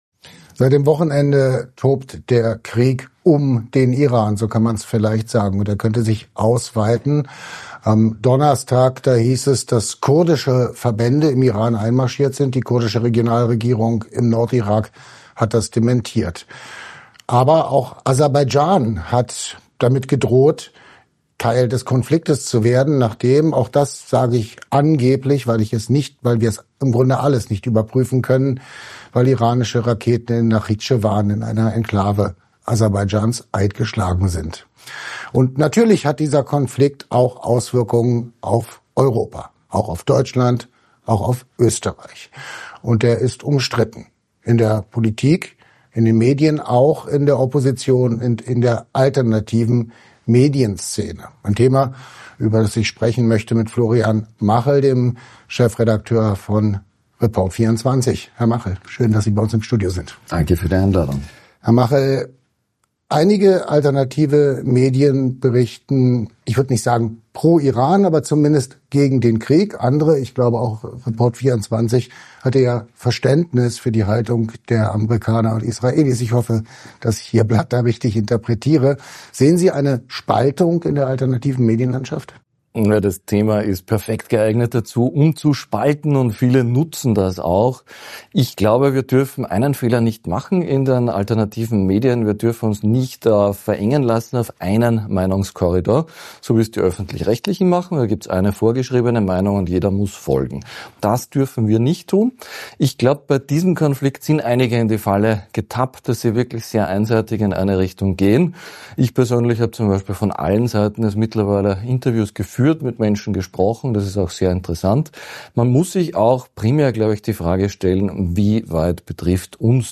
Weshalb er den Waffengang dennoch befürwortet – und weshalb er in der Folge Terroranschläge in Österreich nicht ausschließt –, erfahren Sie in diesem Interview.